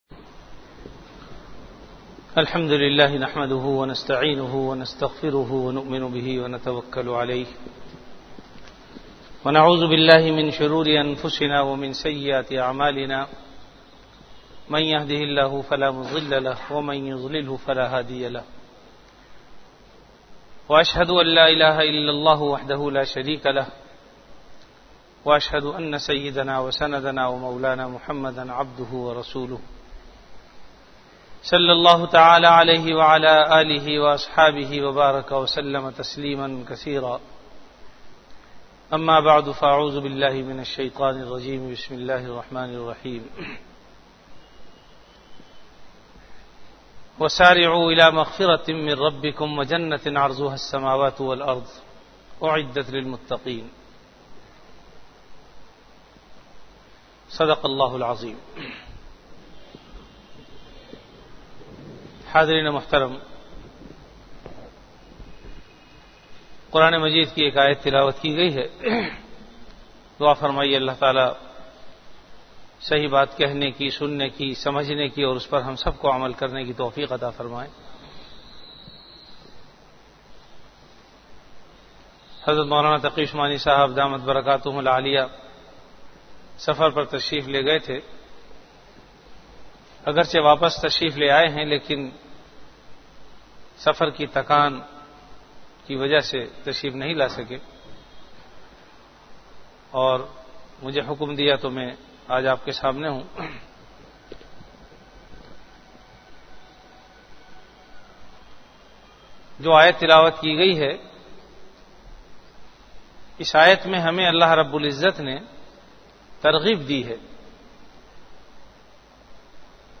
Bayanat · Jamia Masjid Bait-ul-Mukkaram, Karachi
Event / Time Before Juma Prayer